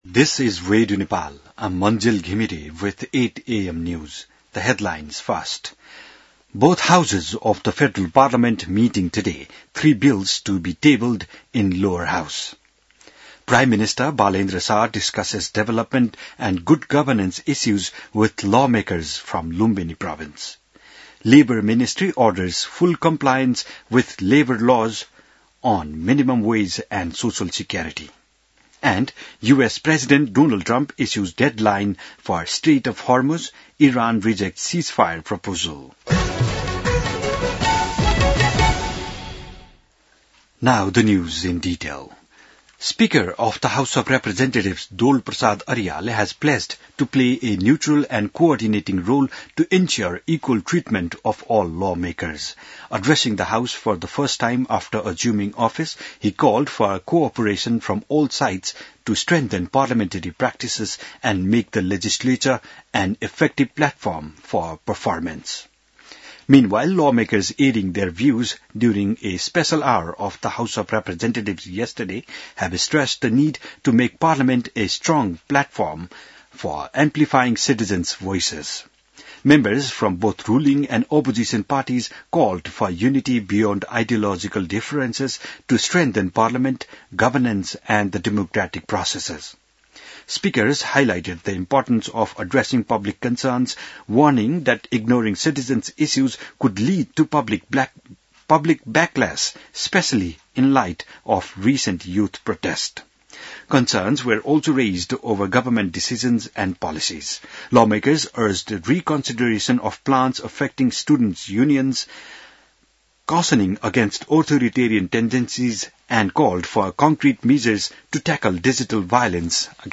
बिहान ८ बजेको अङ्ग्रेजी समाचार : २४ चैत , २०८२